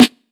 Snares
Box_Snr.wav